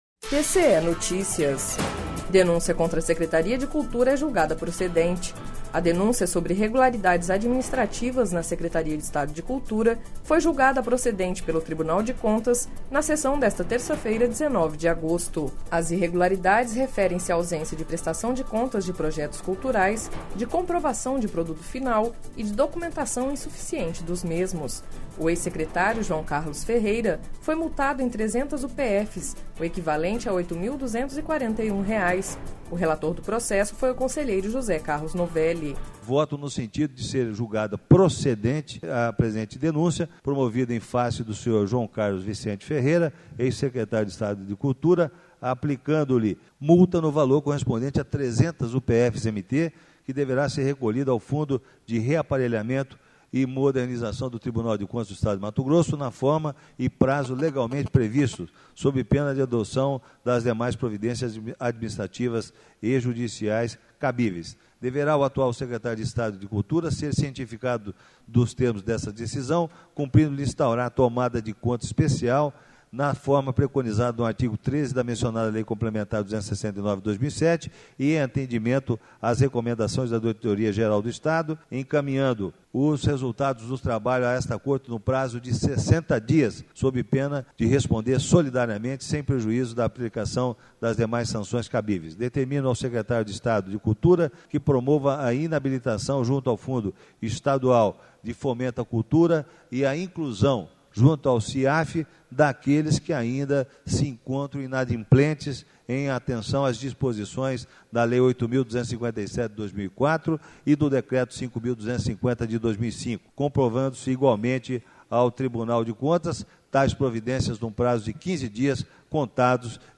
Sonora: José Carlos Novelli - conselheiro TCE-MT